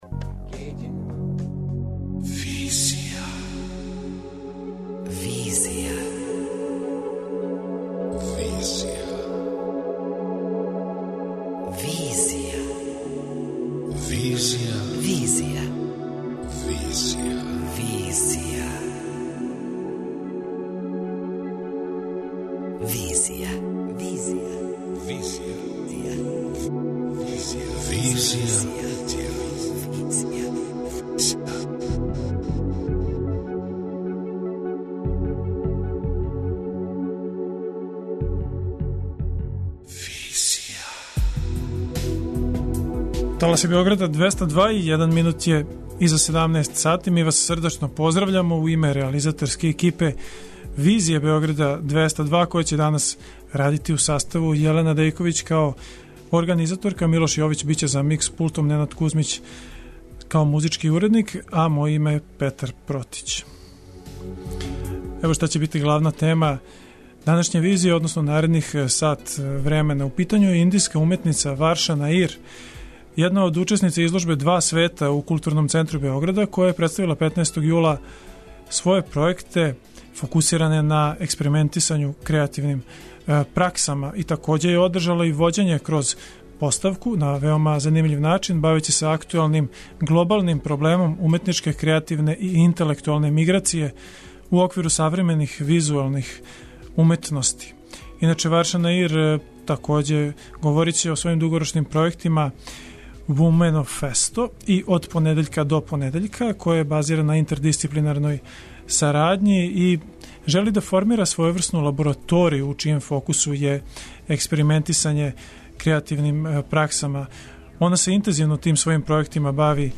Све ово нам је био довољан повод да разговарамо са овом необичном уметницом за данашњу Визију.